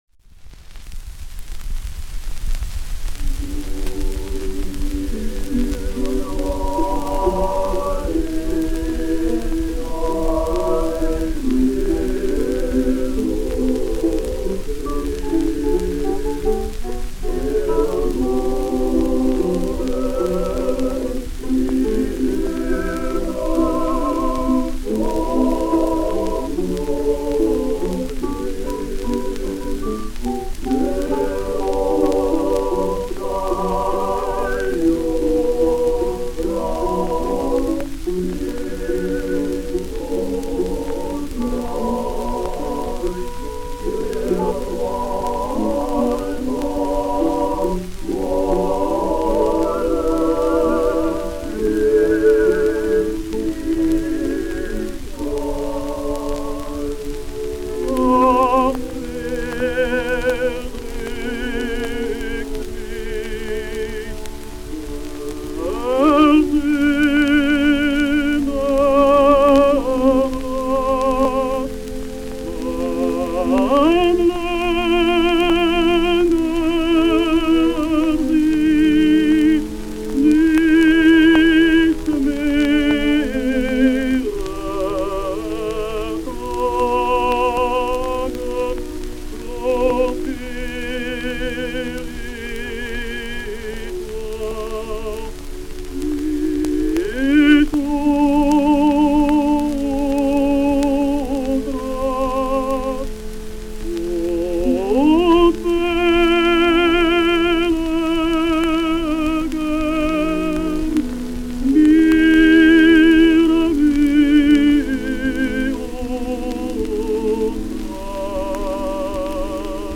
With his somewhat brittle voice, he achieves nonetheless quite respectable results. He resorts to the common vocal technique of using chest voice also in the upper register, as soon as forte notes are required. Logically, this involves considerable muscular tension and forceful pushing.